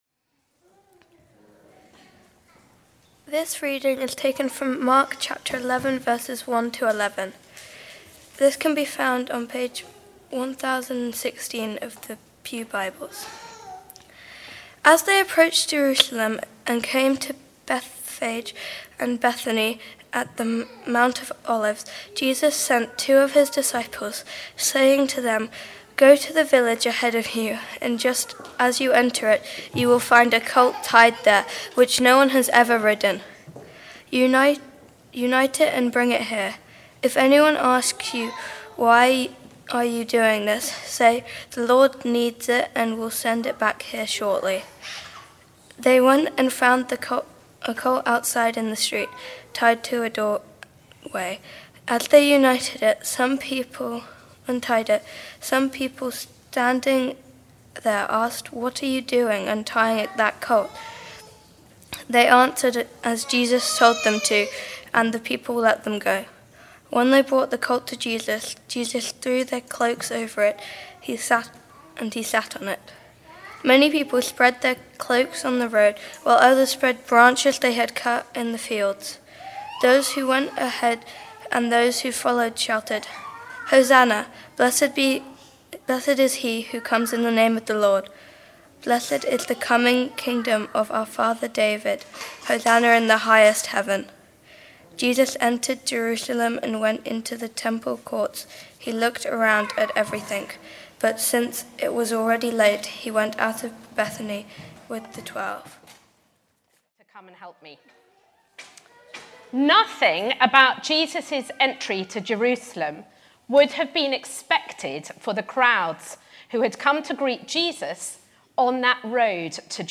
St Mary’s Church Wargrave
Hosanna Sermon